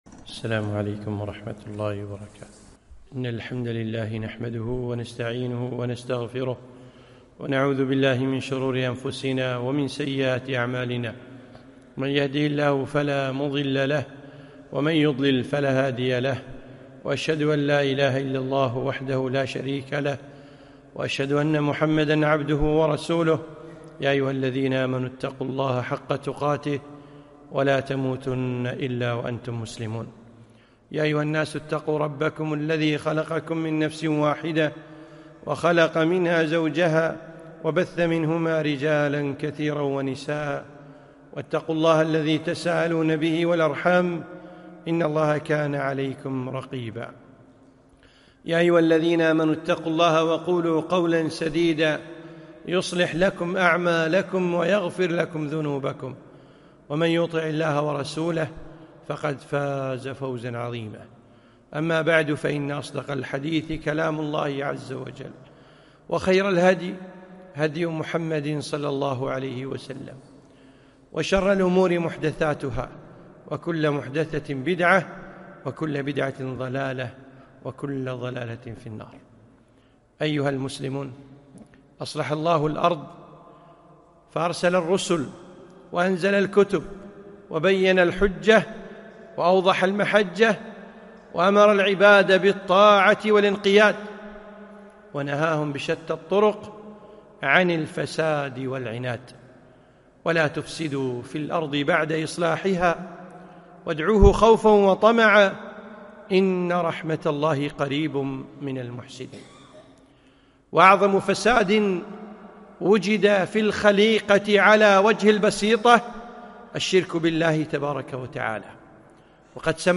خطبة - (الفساد والإفساد)